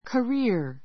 career kəríə r カ リ ア （ ⦣ アクセントの位置に注意） 名詞 ❶ 経歴; 生涯 しょうがい Benjamin Franklin had an interesting career.